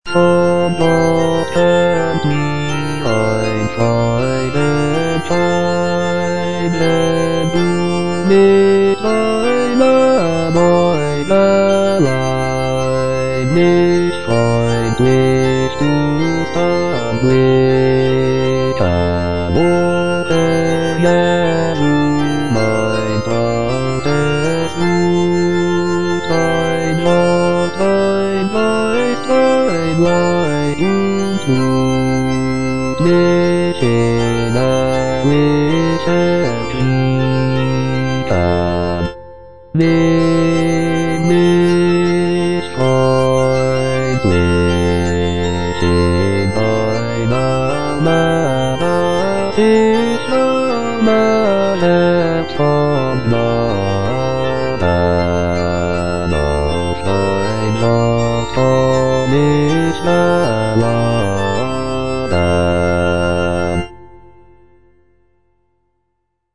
Choralplayer playing Cantata
J.S. BACH - CANTATA "ERSCHALLET, IHR LIEDER" BWV172 (EDITION 2) Von Gott kömmt mir ein Freudenschein - Bass (Voice with metronome) Ads stop: auto-stop
The music is characterized by its lively rhythms, rich harmonies, and intricate counterpoint.